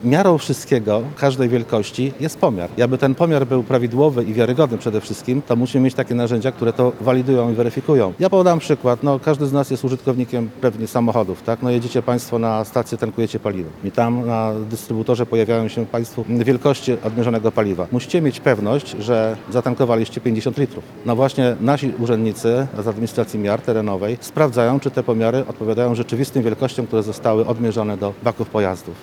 Specjaliści przez dwa dni dyskutowali o współczesnych wyzwaniach metrologii oraz jej roli w rozwoju przemysłu. W Lublinie odbył się Kongres Gospodarczy „Metrologia Przyszłości”.
Eksperci debatują w Lublinie [ZDJĘCIA] Kongres odbył się w Lubelskim Centrum Konferencyjnym.